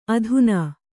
♪ adhunā